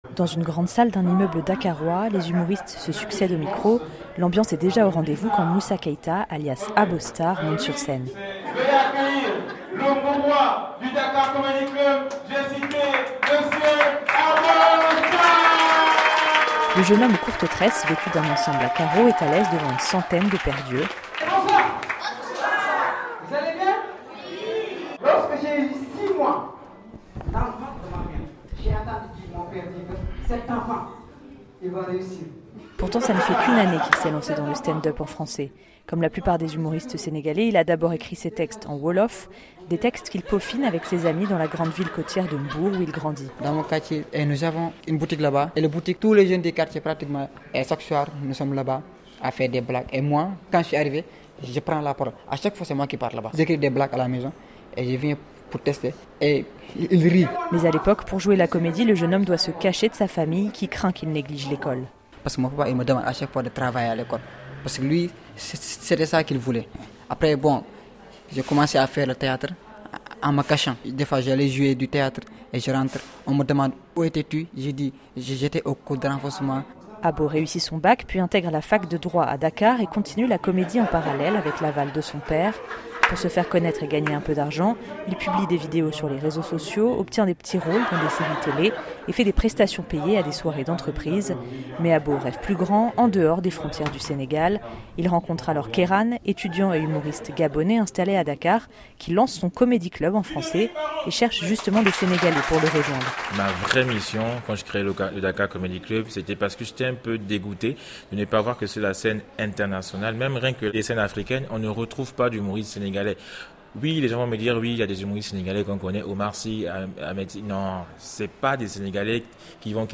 Reportage à Dakar.